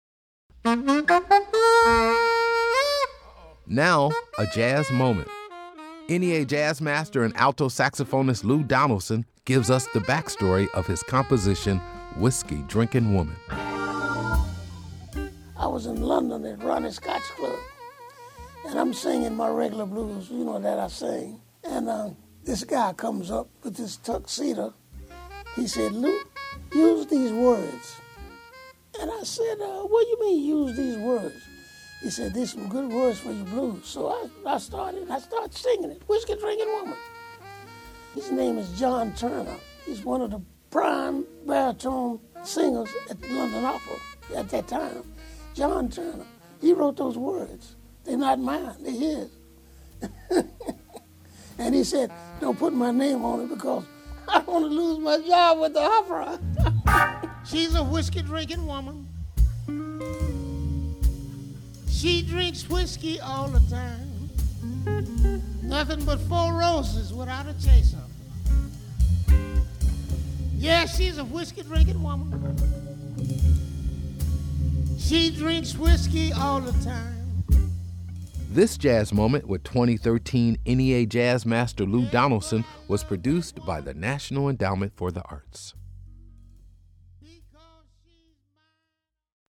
Excerpt of "Whiskey Drinkin' Woman" composed by Lou Donaldson from the album, Live on the QE2, used courtesy of Chiaroscuro Records and by permission of Tunemaker Music. (BMI).